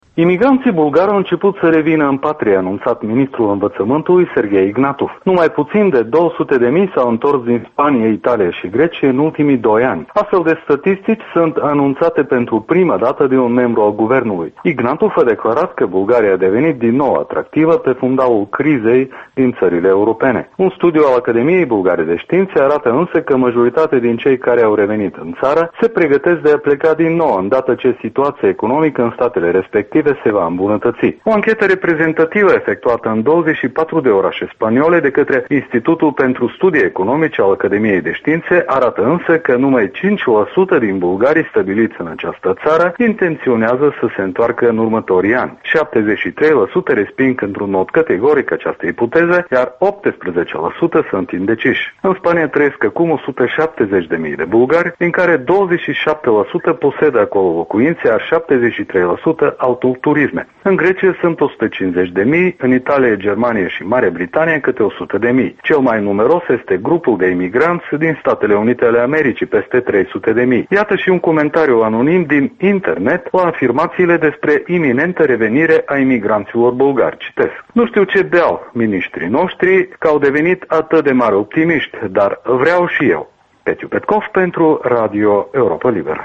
Jurnal de corespondent: Bulgaria și revenirea imigranților